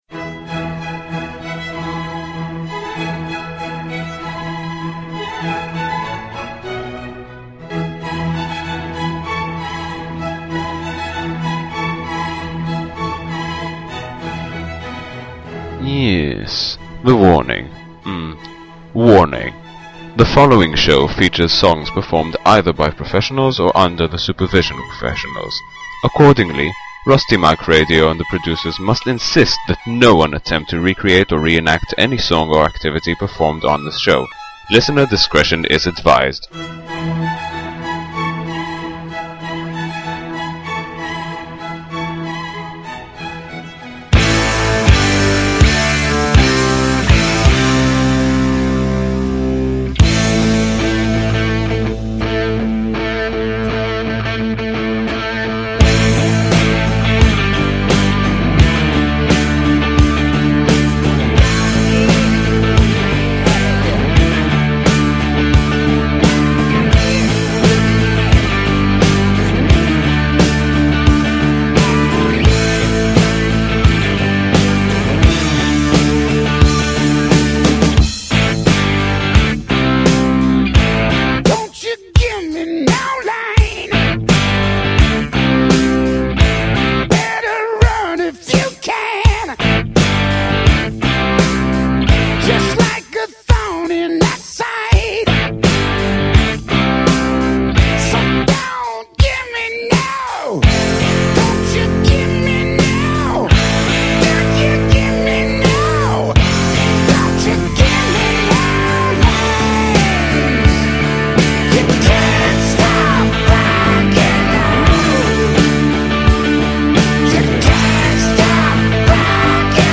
And Rock Music!!!!